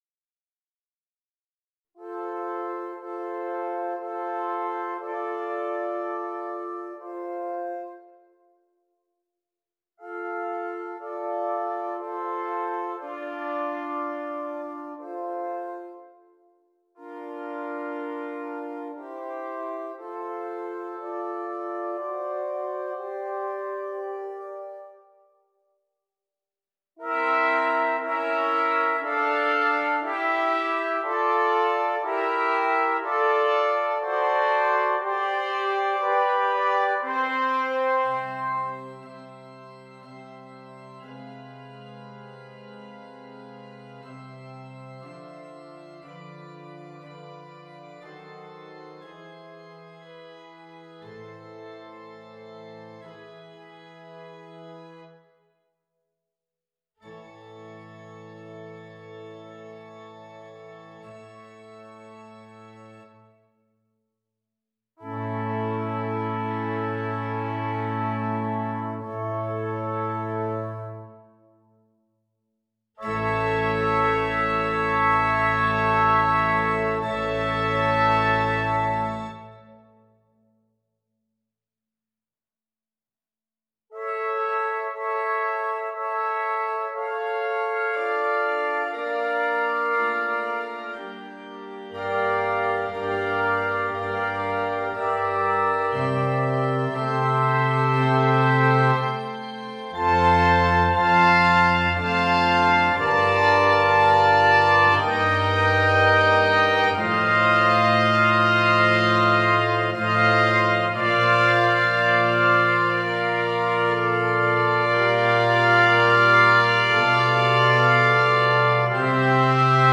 Brass Quintet and Organ
It has been arranged here for quintet and organ.